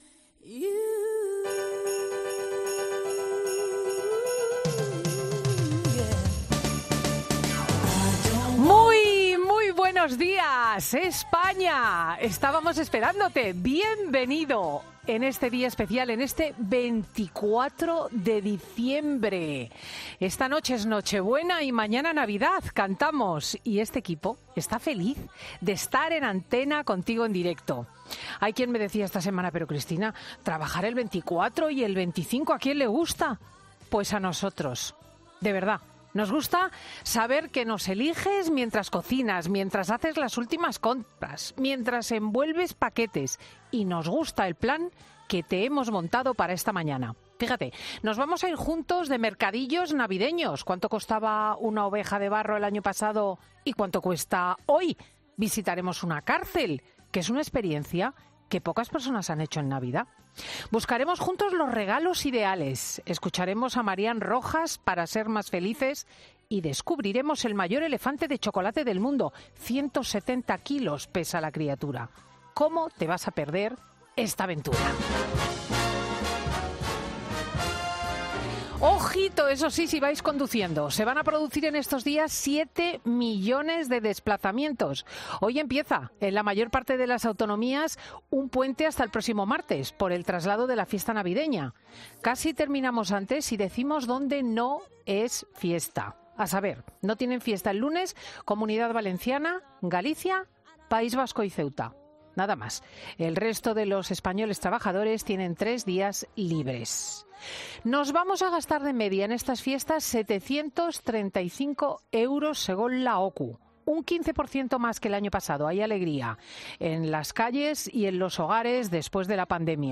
AUDIO: Ya puedes escuchar le monólogo de Cristina López Schlichting de este sábado de Nochebuena.